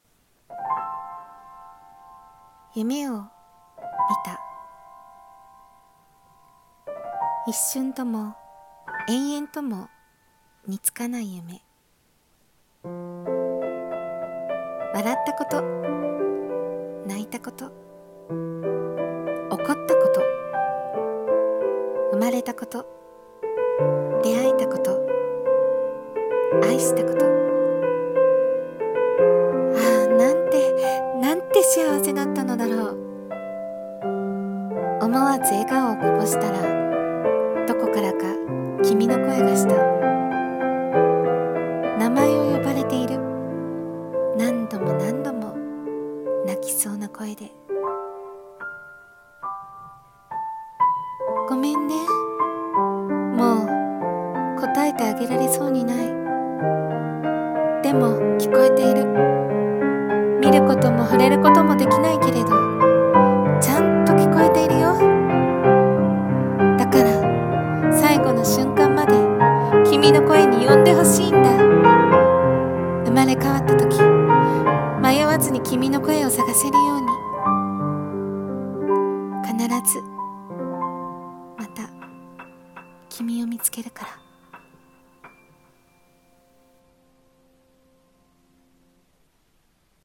朗読台本「いのちの名前」